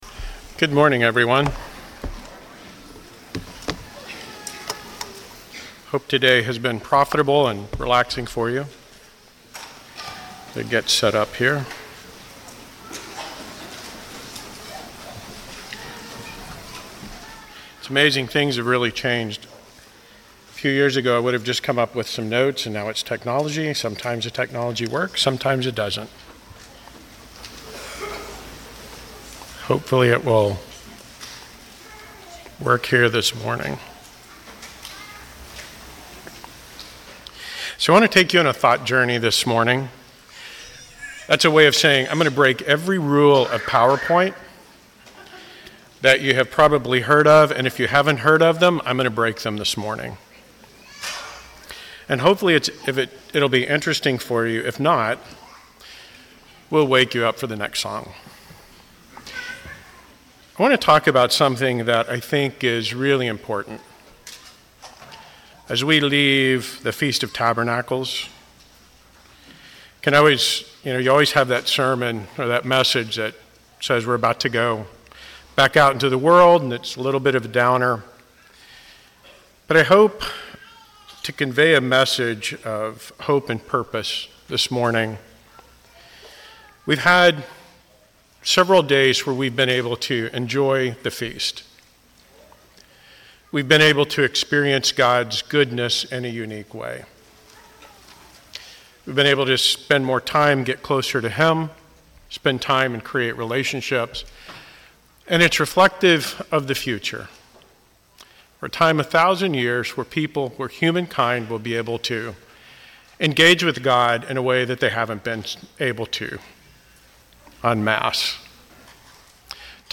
Sermons
Given in Lihue, Hawaii